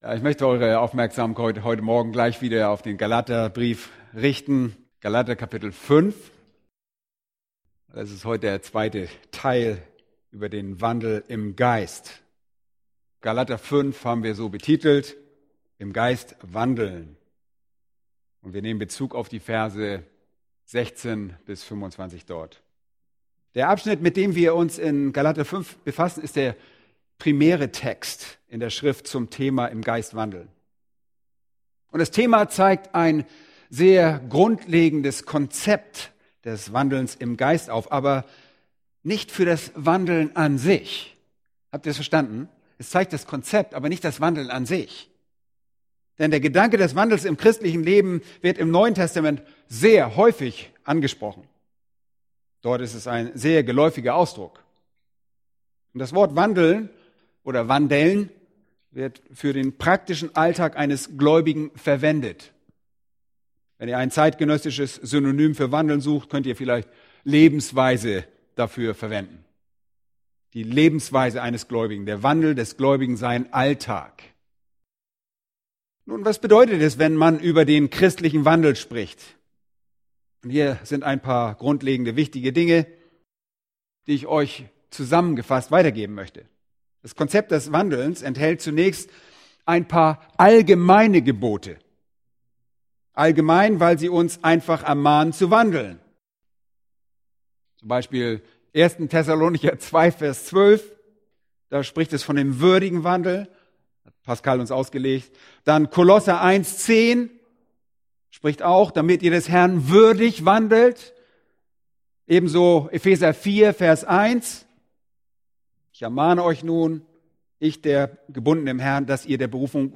Eine predigt aus der serie "Freiheit in Christus*." Galater 5,19-21